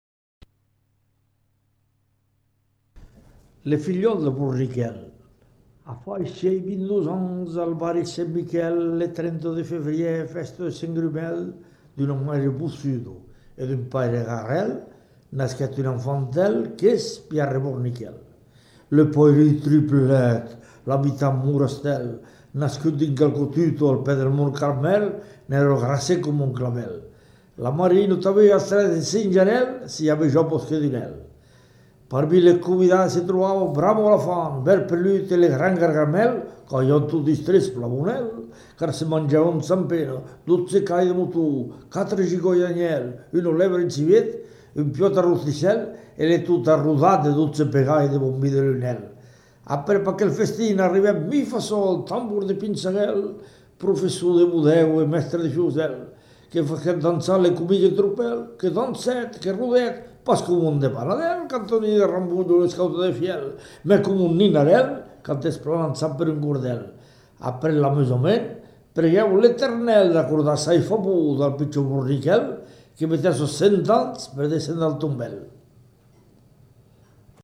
Lieu : Caragoudes
Genre : poésie
Type de voix : voix d'homme
Production du son : récité